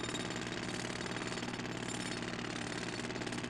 BackSound0673.wav